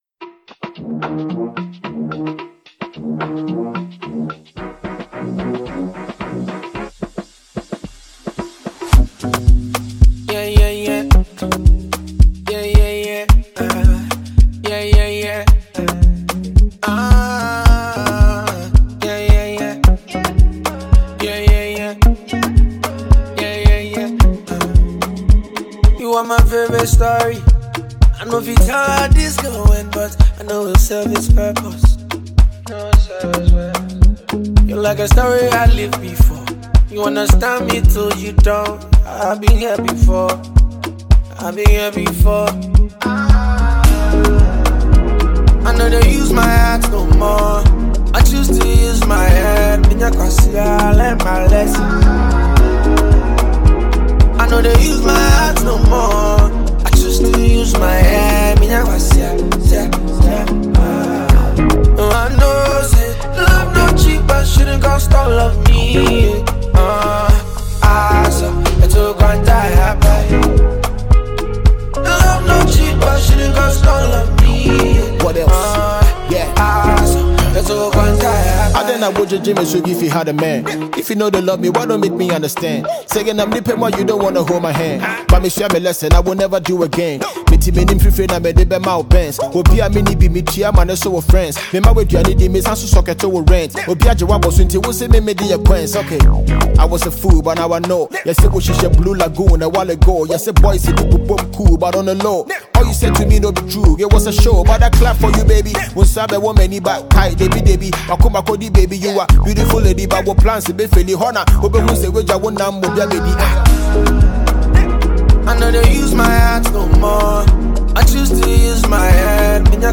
Prominent Ghanaian Singer, and Songwriter